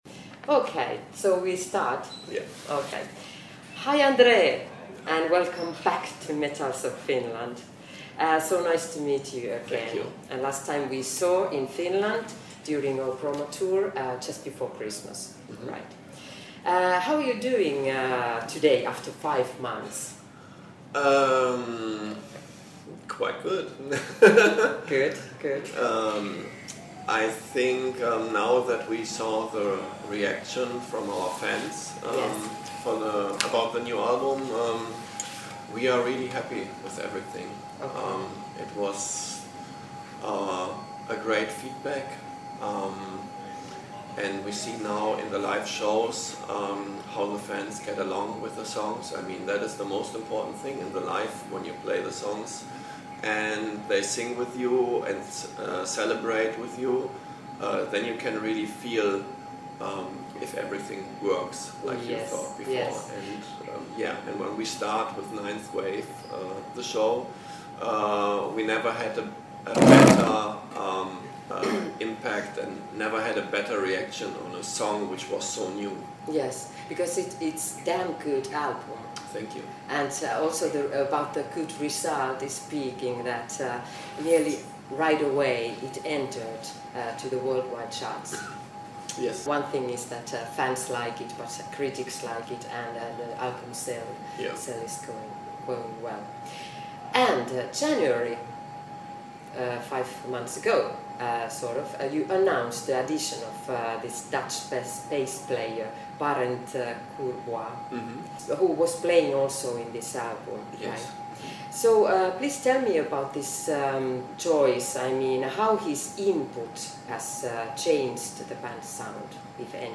interview-blind-guardian-andre-5may2015-edited.mp3